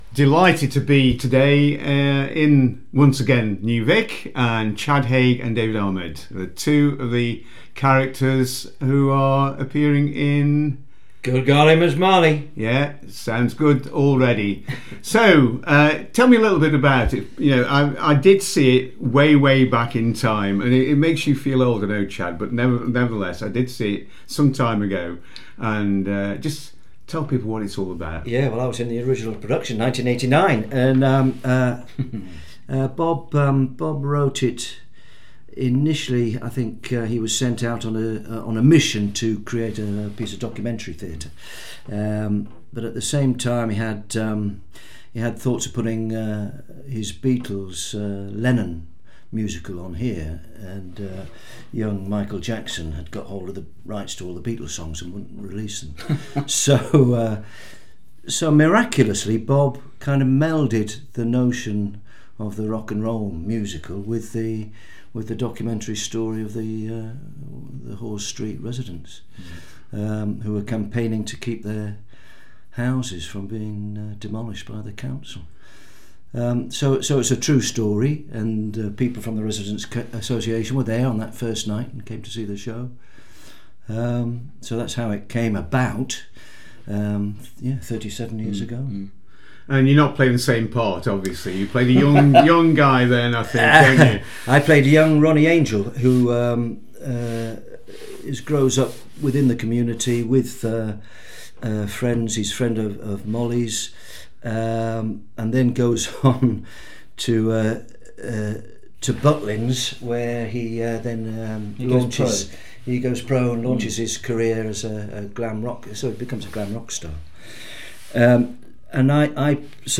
Recently I managed to chat to a couple of members of the cast about this upcoming production.